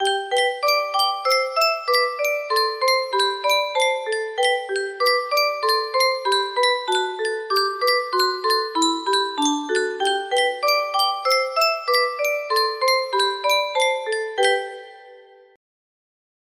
Clone of Yunsheng Spieluhr - Morgen kommt der Weihnachtsmann music box melody